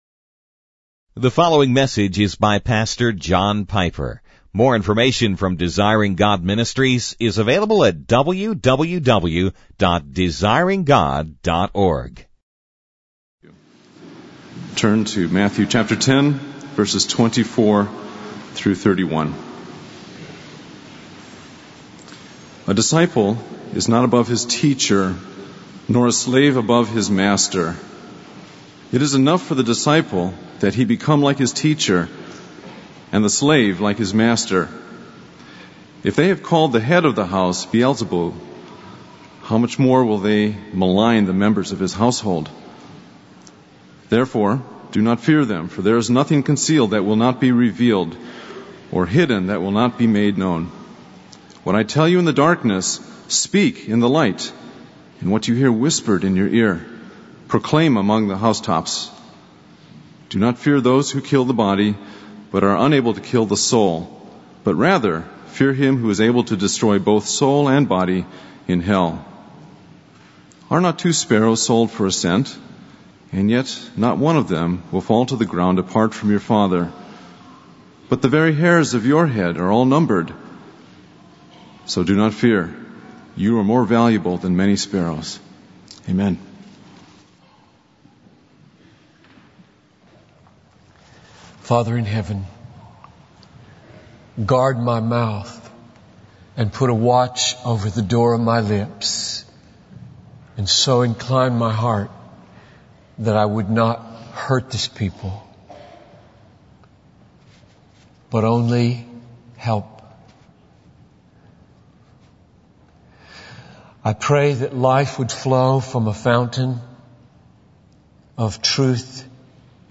In this sermon, Pastor John Piper addresses the issue of questioning God's sovereignty in the face of tragedy. He shares the heartbreaking story of a widower whose wife and infant daughter were mistakenly shot down by the Peruvian Air Force. Piper emphasizes that God is always working in mysterious ways for the good of his children, even when we cannot understand or see it.